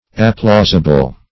Applausable \Ap*plaus"a*ble\, a. Worthy of applause; praiseworthy.
applausable.mp3